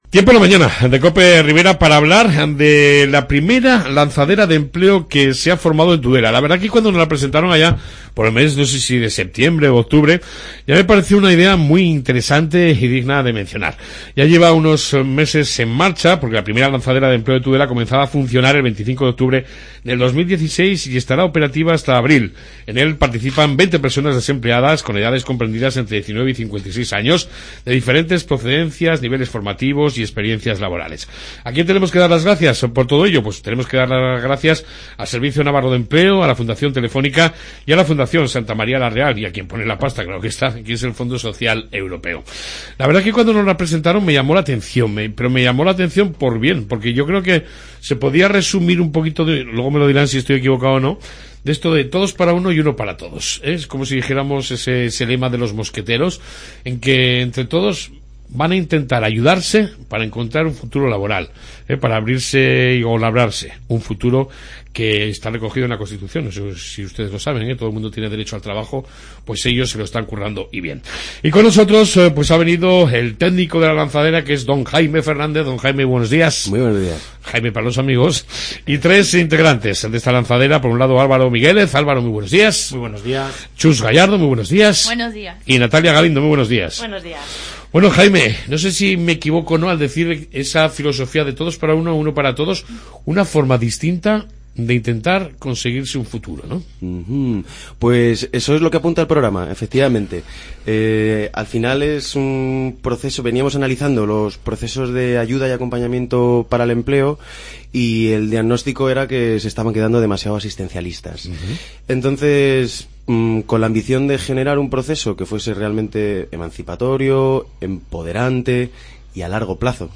AUDIO: Entrevista con miembros de la 1ª lanzadera de Empleo de Tudela.